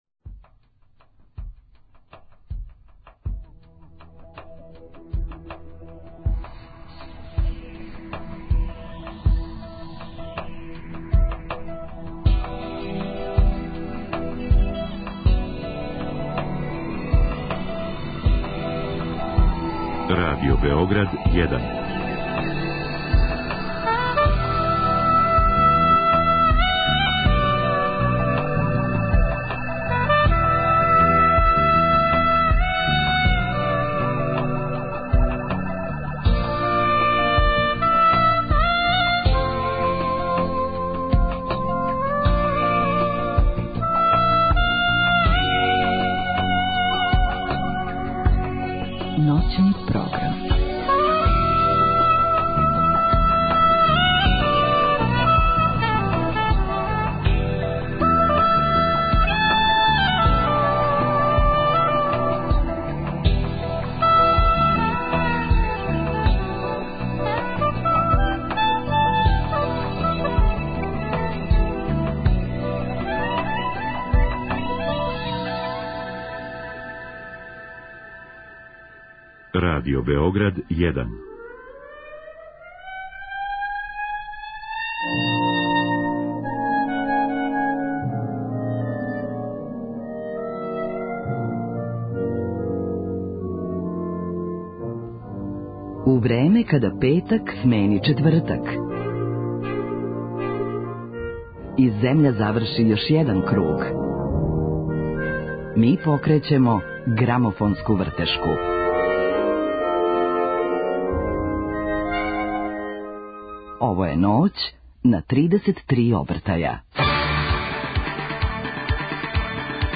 Ноћас ћемо слушати плоче које су обележиле те узбудљиве месеце када су деца цвећа од чудака са маргине постали првоборци за мир, солидарност и хуманост.